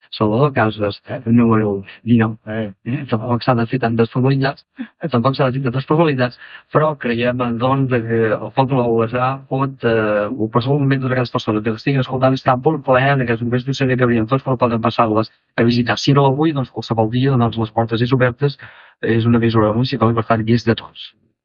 Programa especial el dia de la inauguració dels nous estudis. Inivitació a la població que els visiti
FM